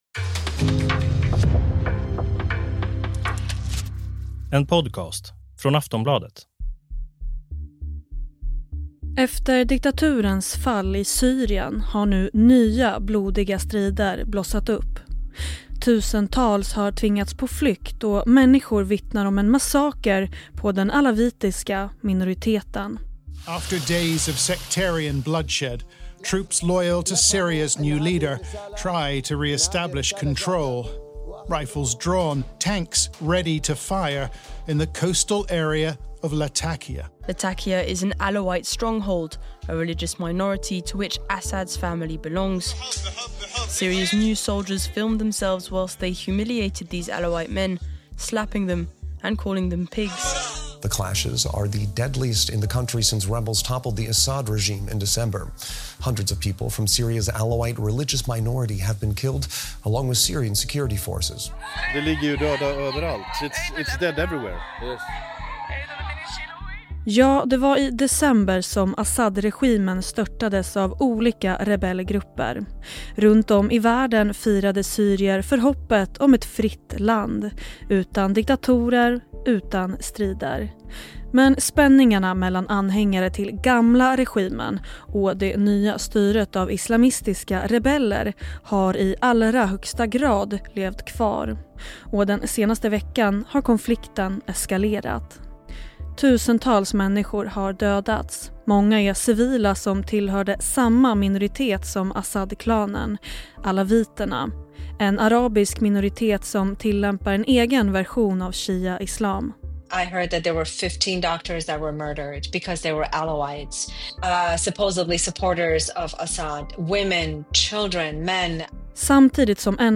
Klipp i avsnittet: Sky News, France 24, DW News, CBN News, Sveriges Radio.